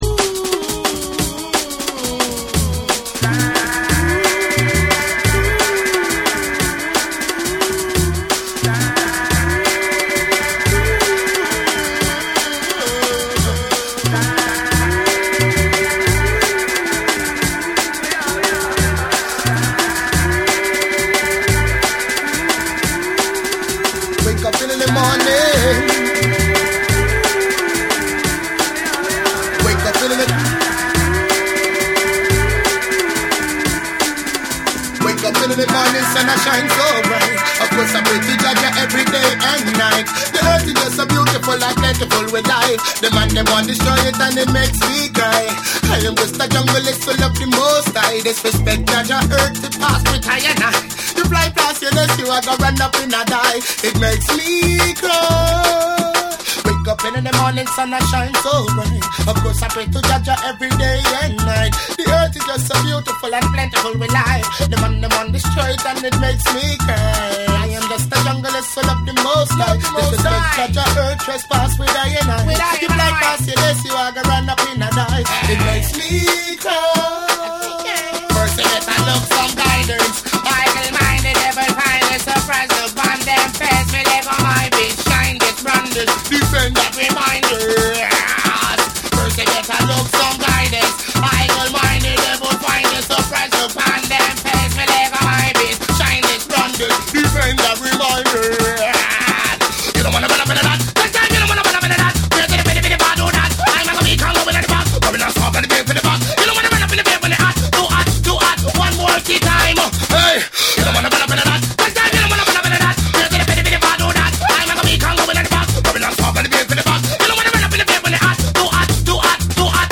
JUNGLE & DRUM'N BASS